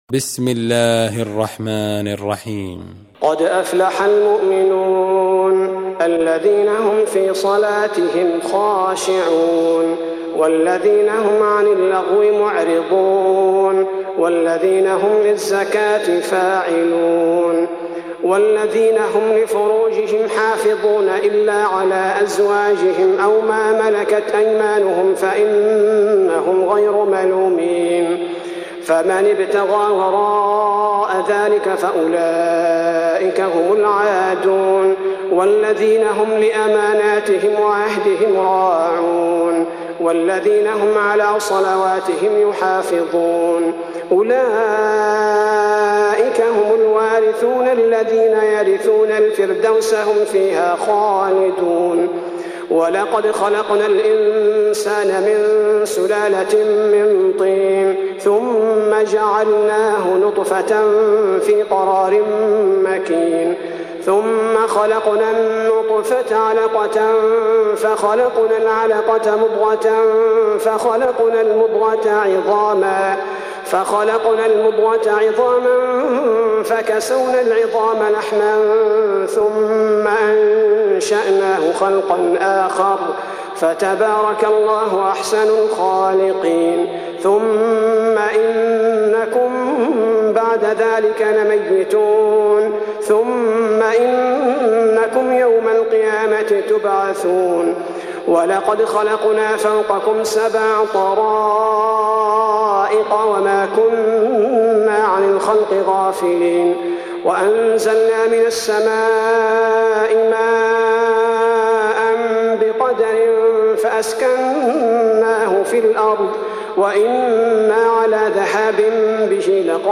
Surah Repeating تكرار السورة Download Surah حمّل السورة Reciting Murattalah Audio for 23.